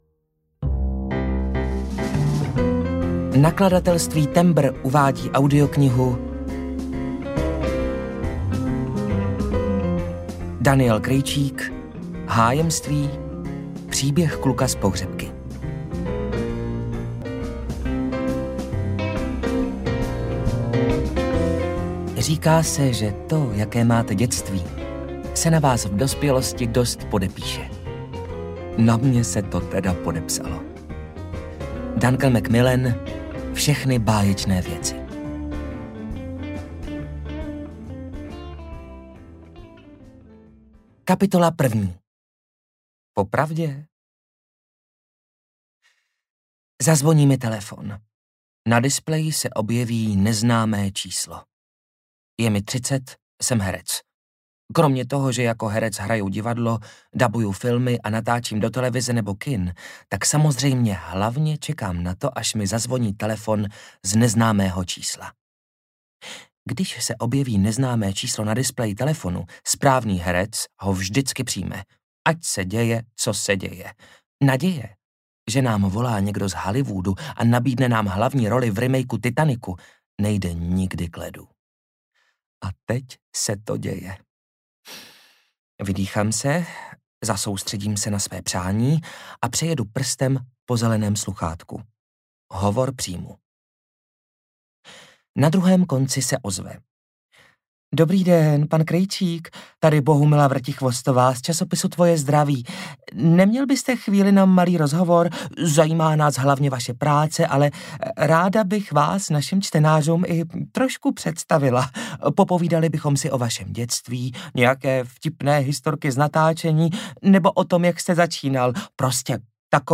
Hájemství audiokniha
Ukázka z knihy